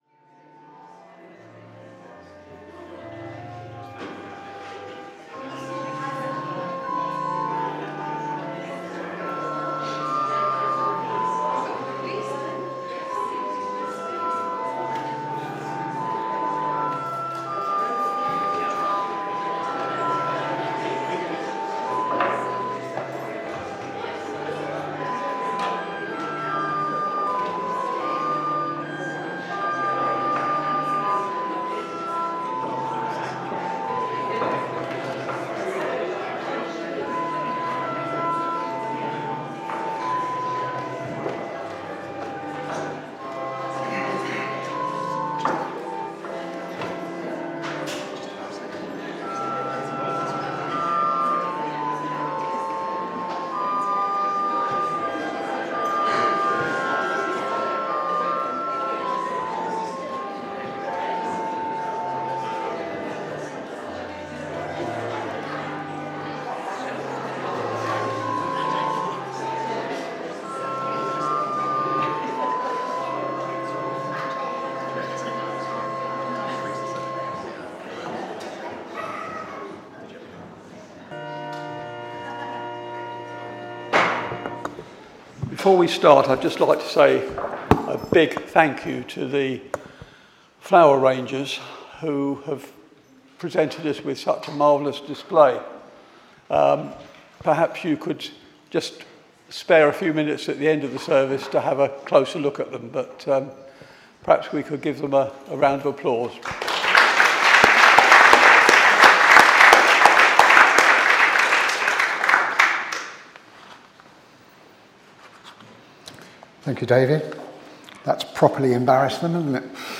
Sunday Audio Service – Trinity 5 – Holy Communion – 30/6/24